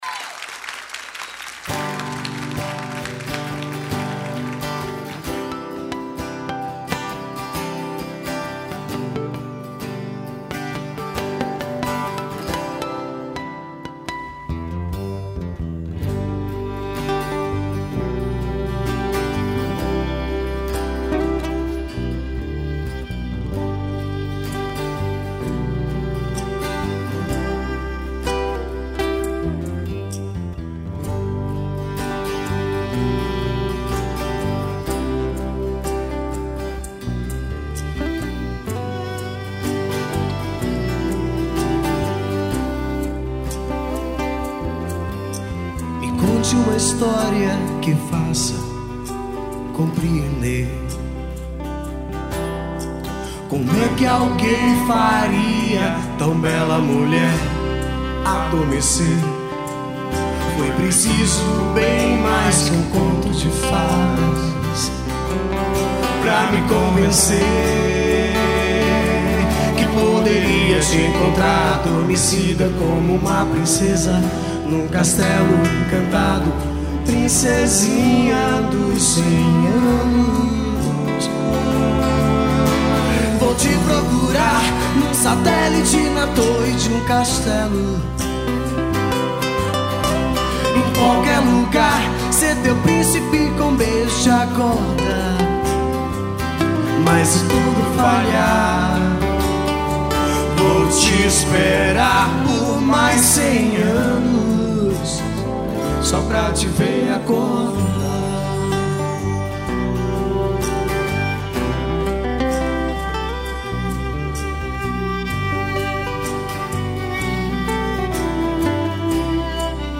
2844   03:49:00   Faixa: 2    Mpb